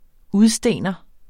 Udtale [ ˈuðˌsdeˀnʌ ]